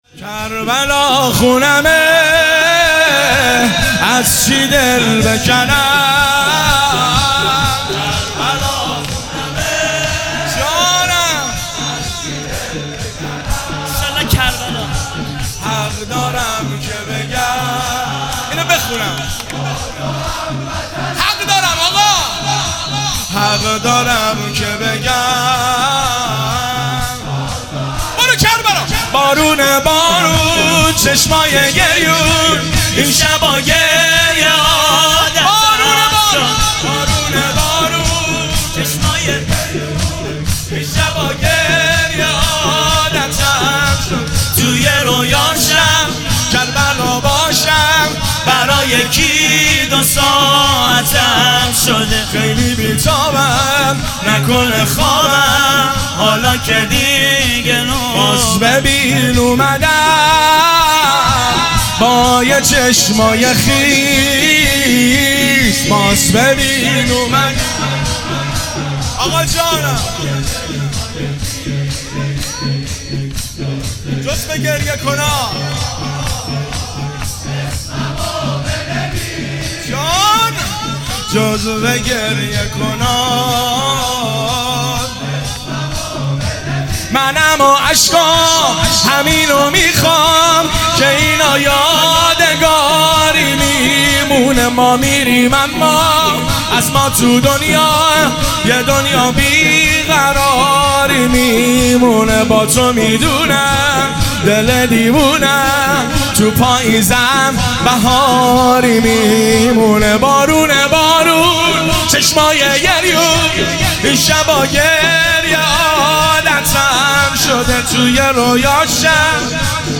هیئت ام المصائب (س) بابل